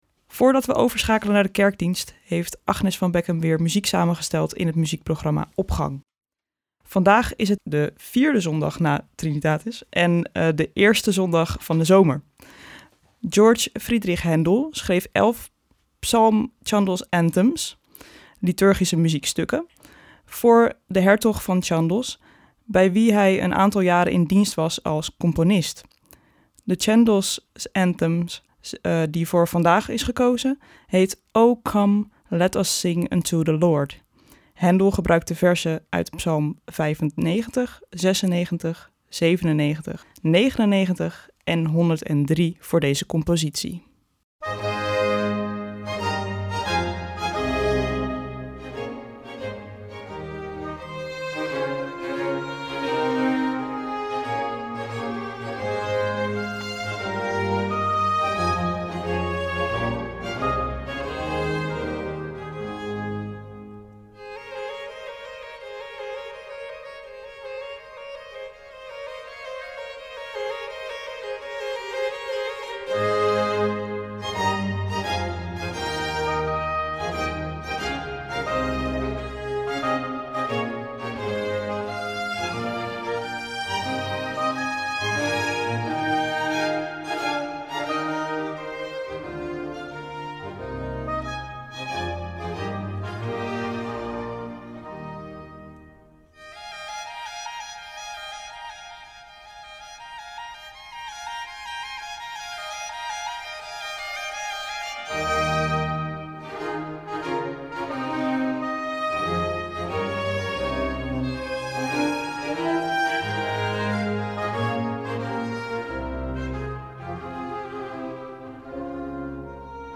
Opening van deze zondag met muziek, rechtstreeks vanuit onze studio.
Georg Friedrich Händel schreef elf Psalm Chandos Anthems, (liturgische muziekstukken) voor de hertog van Chandos bij wie hij een aantal jaren in dienst was als componist. De Chandos Anthem die voor vandaag gekozen is heet O come, let us sing unto the Lord.